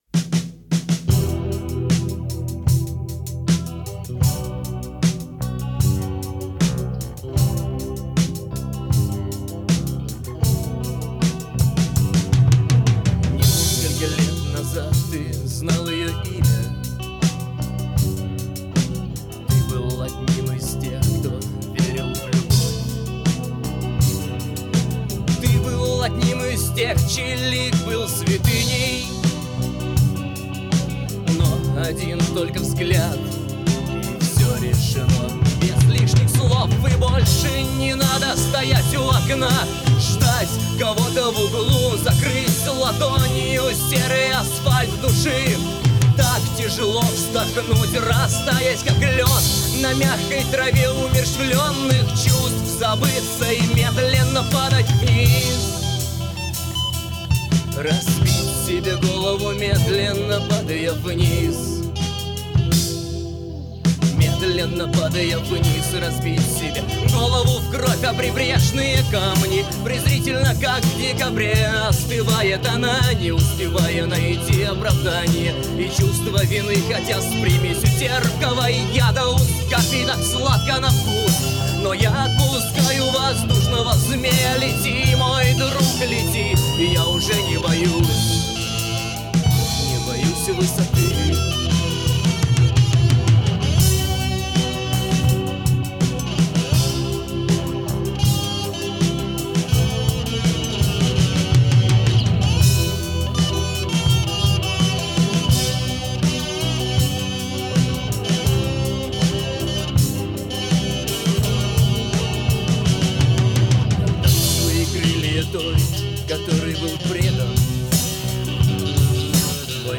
слова*, музыка, голос,
ритм-гитара, соло-гитара, акустическая гитара,
аккордеон.
ударные, шейкер, бэк-вокал (8), sound design,
Запись произведена летом 1999 года и весной 2000 года в